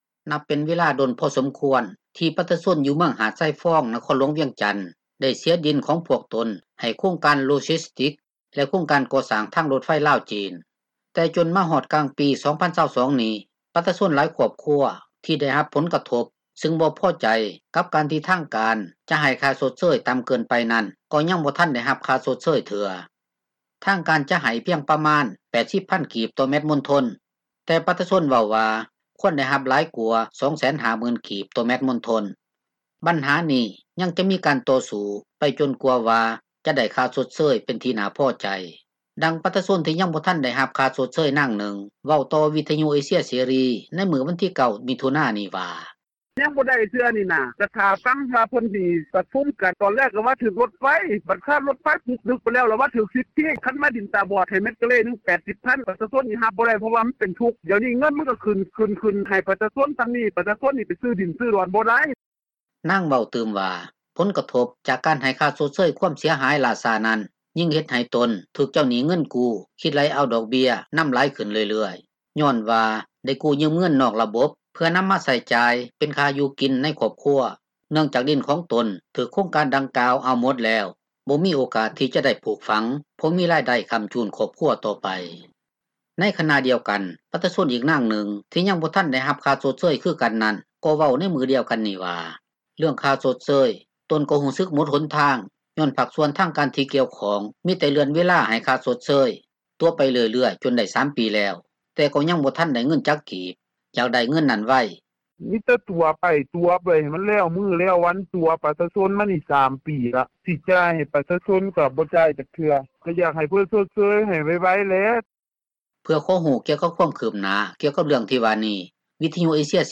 ດັ່ງປະຊາຊົນ ທີ່ຍັງບໍ່ທັນໄດ້ຮັບຄ່າຊົດເຊີຍ ນາງນຶ່ງ ເວົ້າຕໍ່ວິທຍຸເອເຊັຽເສຣີໃນມື້ວັນທີ 9 ມິຖຸນານີ້ວ່າ:
ດັ່ງປະຊາຊົນທີ່ໄດ້ຮັບຜົລກະທົບ ຜູ້ນຶ່ງເວົ້າໃນມື້ດຽວກັນນີ້ວ່າ: